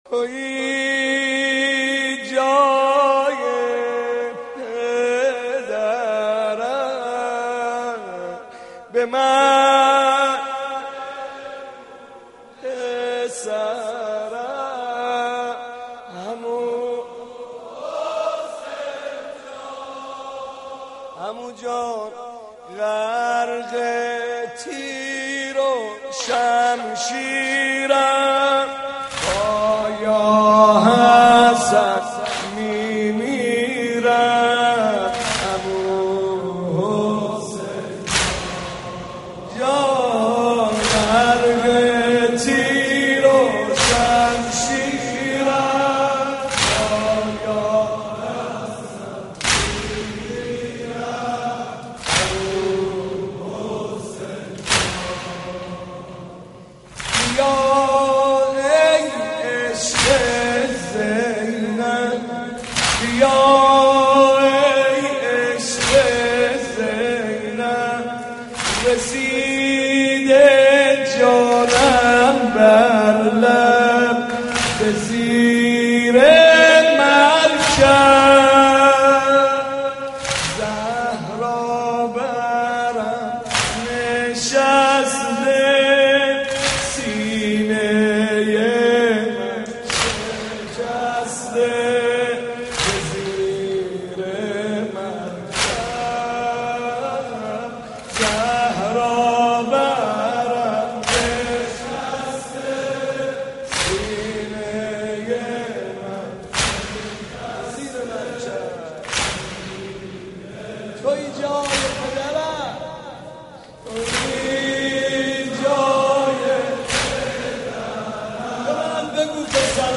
محرم 88 - سینه زنی 4